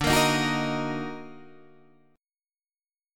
Ebm/D chord